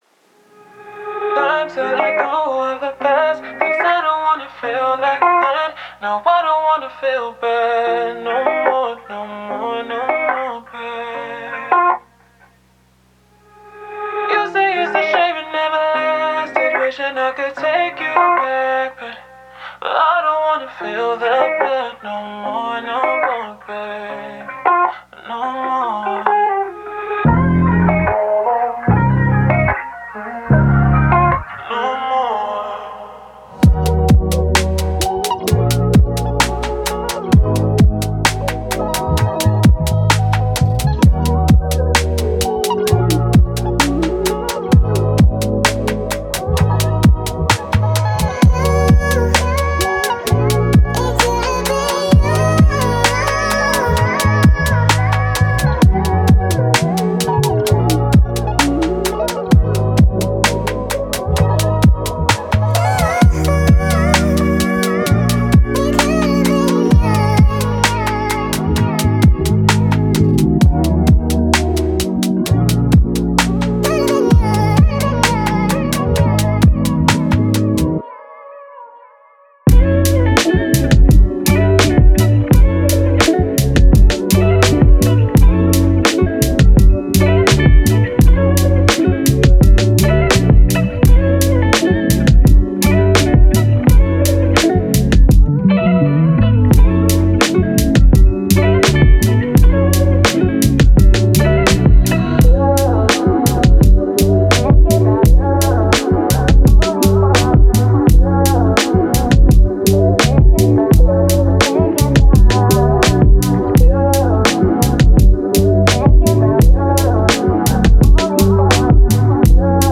Genre:Soul and RnB
録音とミックスはイギリス、アメリカ、スペインで行われました。
デモサウンドはコチラ↓